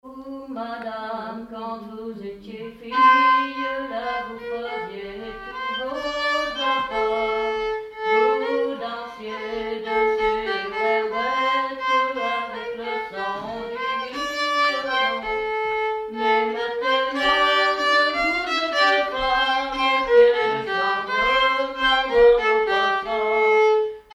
circonstance : bal, dancerie
Pièce musicale inédite